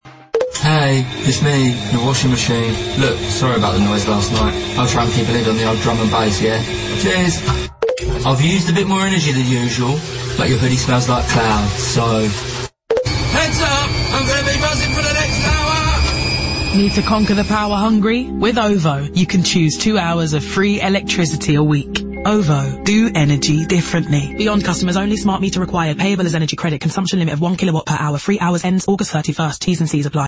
In this playful and inventive 30-second ad, we meet a washing machine who’s both apologetic and self-aware. “Hey, it’s me, your washing machine,” it begins, instantly disarming the listener with a casual, conversational tone.
Snappy pacing and well-crafted sound design keep the spot dynamic, while the anthropomorphic approach gives a memorable twist to what could have been a dry utility message.